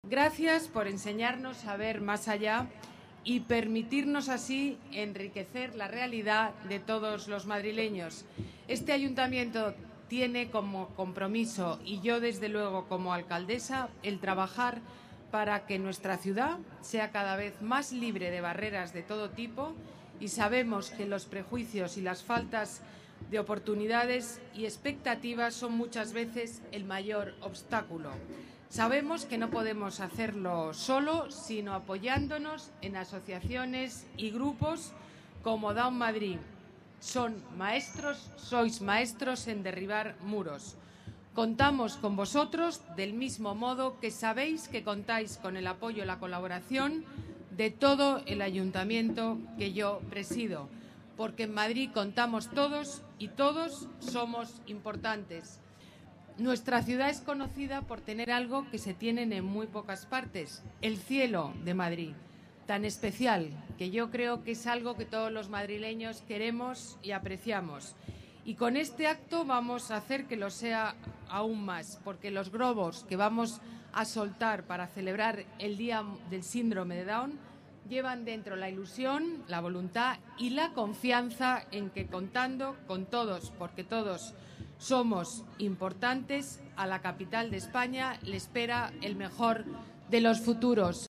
Más archivos multimedia Alcaldesa en el Día Mundial del Síndrome de Down Ana Botella, en el Día Mundial del Síndrome de Down Más documentos Palabras de la alcaldesa en el Día Mundial del Síndrome de Down